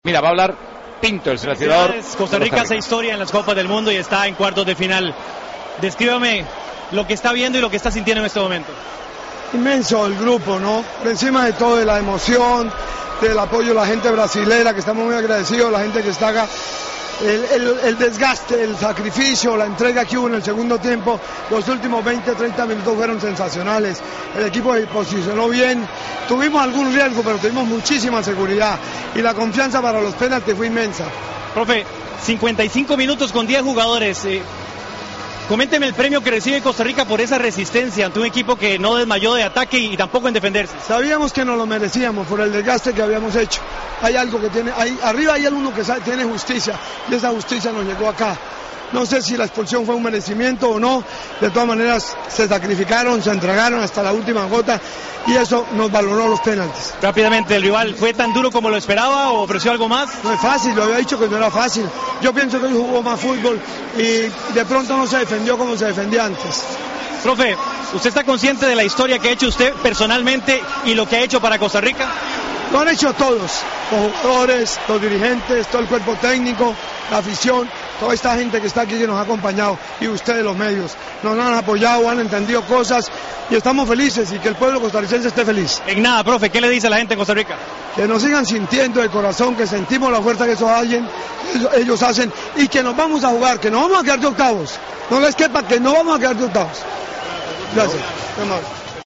El entrenador de Costa Rica se mostró eufórico tras la histórica gesta conseguida por su selección.